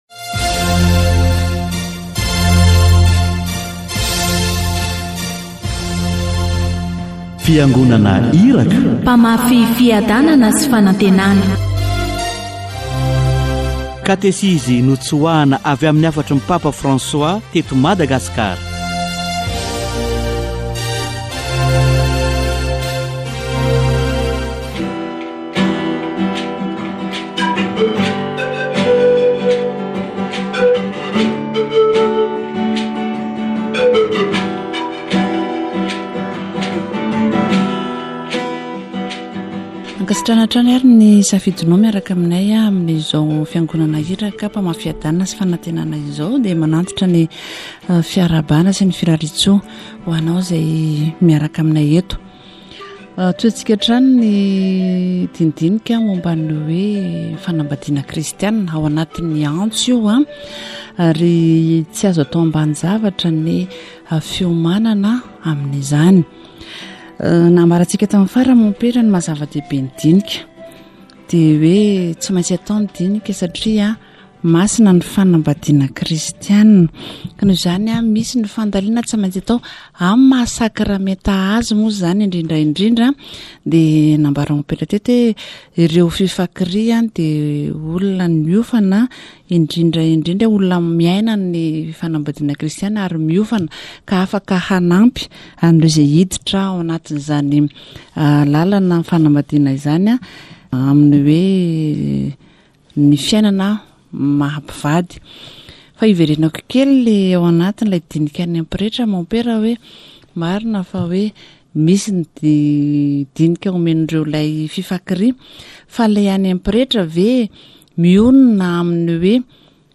Ny laretirety dia isan'ireo fomba nampianarin'ny fiangonana entina handinihan-tena. Katesizy momba ny fahaizana mihaino ny antso